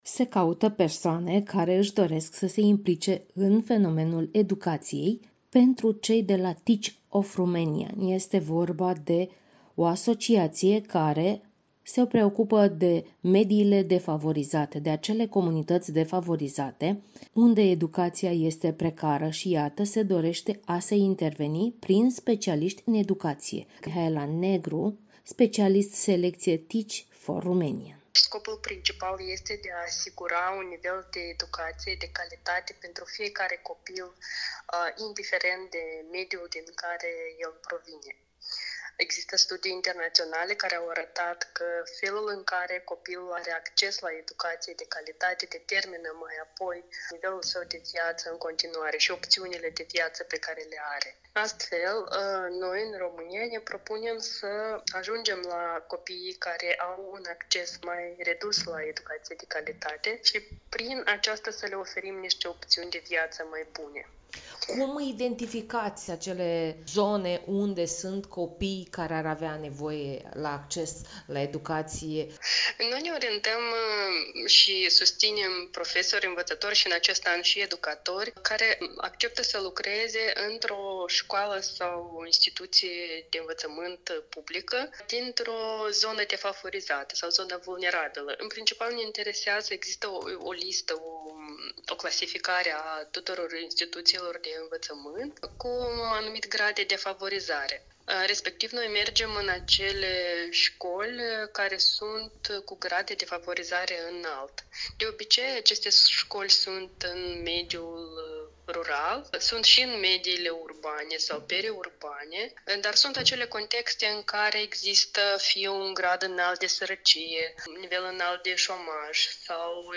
(INTERVIU)Cum schimbă profesorii Teach for Romania viitorul României?
interviul-integral.mp3